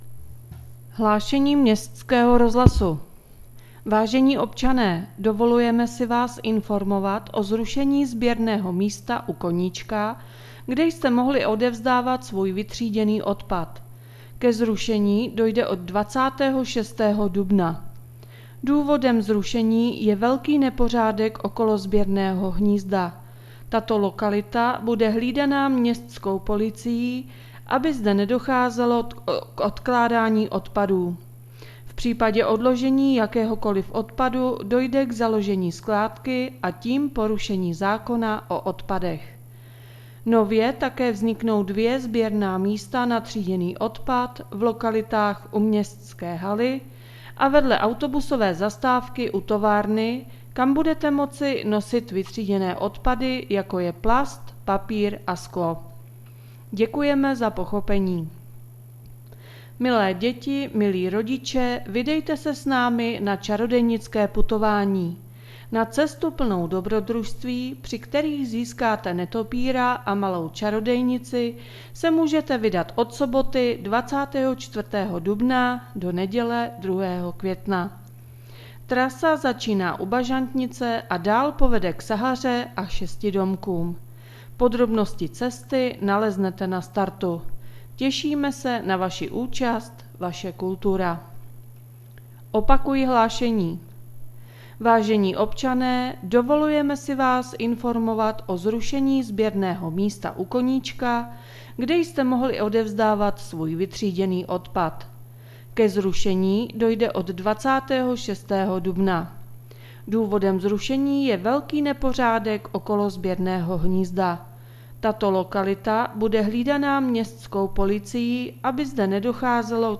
Hlášení městského rozhlasu 23.4.2021